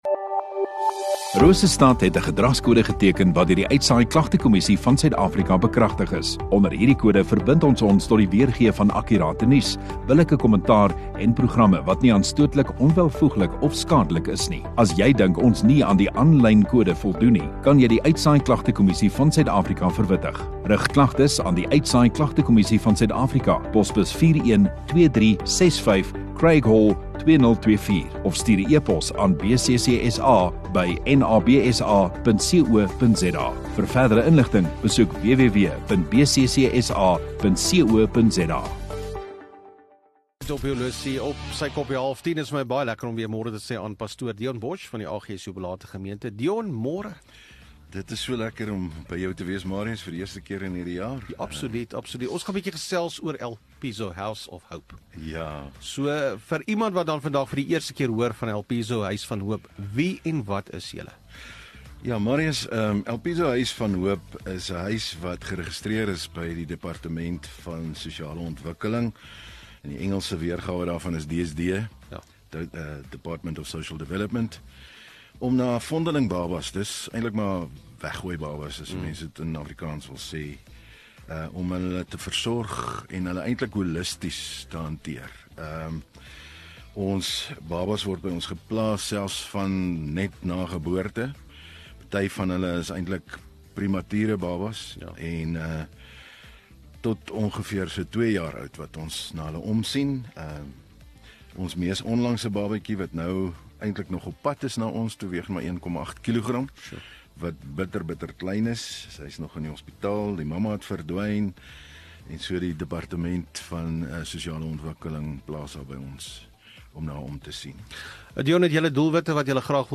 Radio Rosestad View Promo Continue Radio Rosestad Install Gemeenskap Onderhoude 5 Feb Huis van Hoop